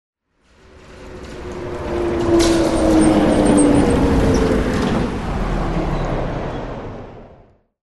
На этой странице собраны звуки троллейбуса: от плавного старта до равномерного движения с характерным гулом.
Шум подъезжающего троллейбуса к остановке